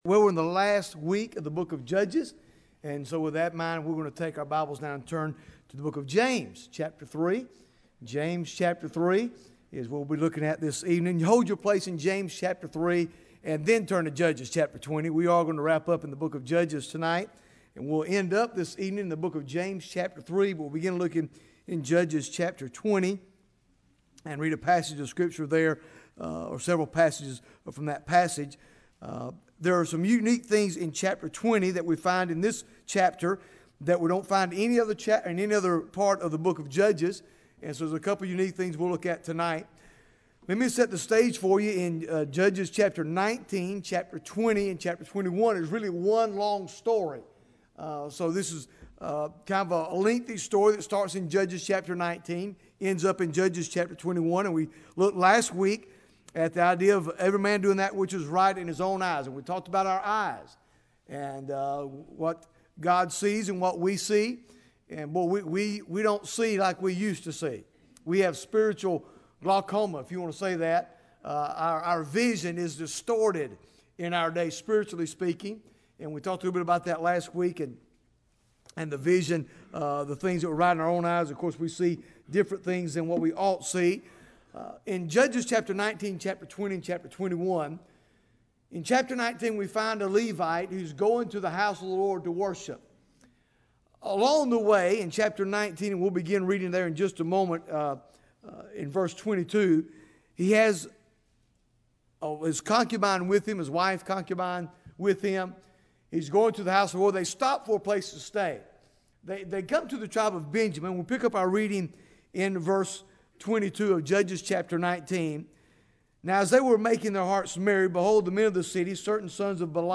Bible Text: James 3 | Preacher